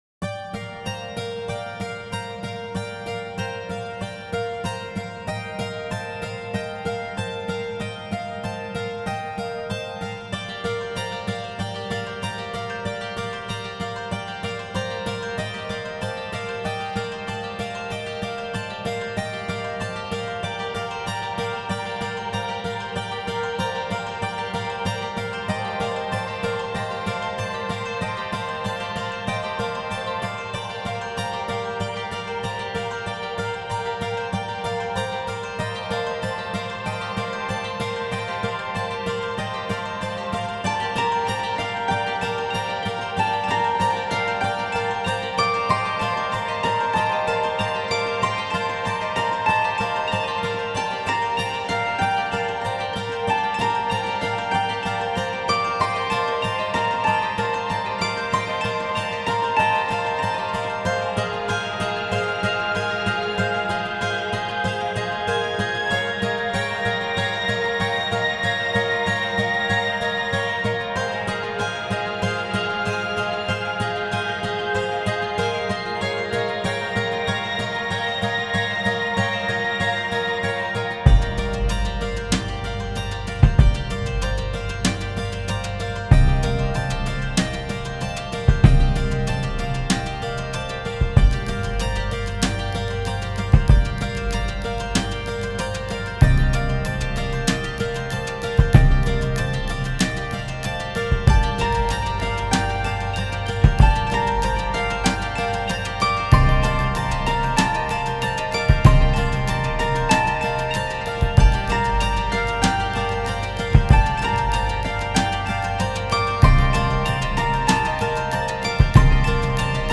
SLOW-TEMPO RELAXING THOUGHTFUL GUITARS BASS STRINGS DRUMS